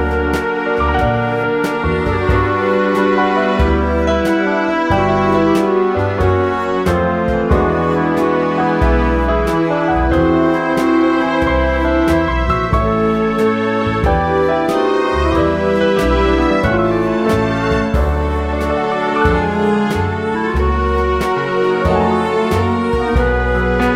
No Backing Vocals Oldies (Female) 2:59 Buy £1.50